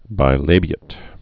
(bī-lābē-ĭt, -āt)